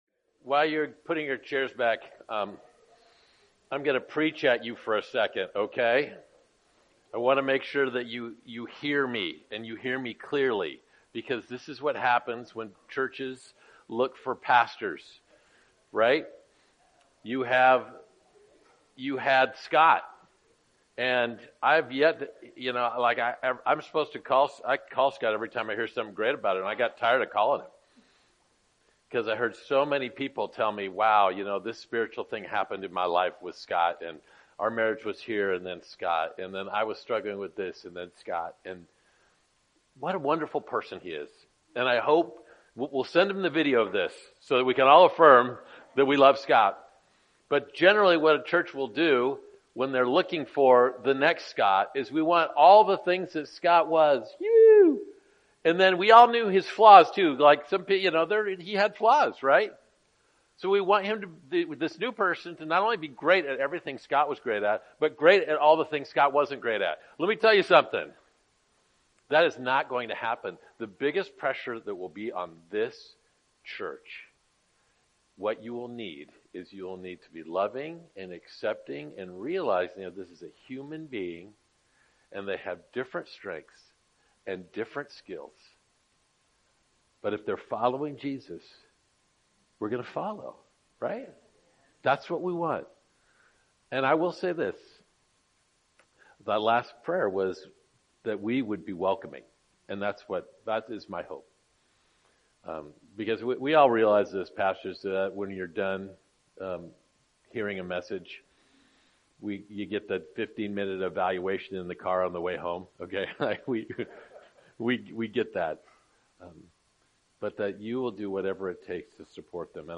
Sermons | Covenant Grove Church